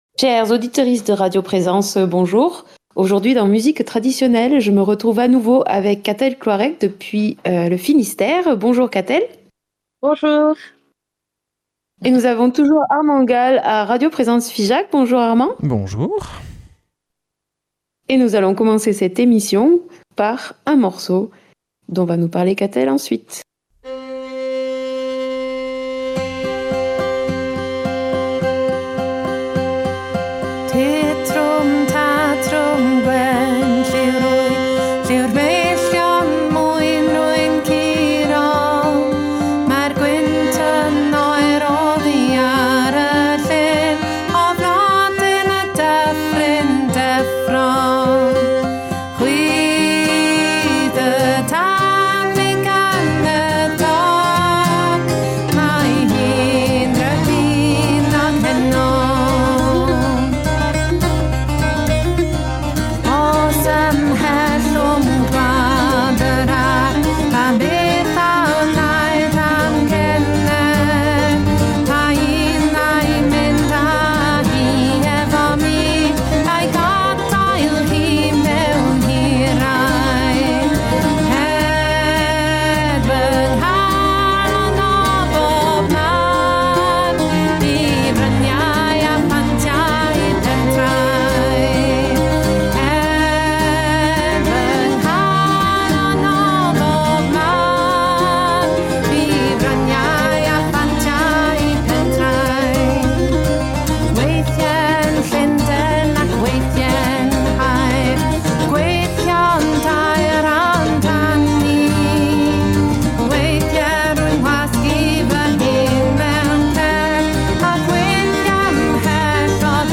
l’entretien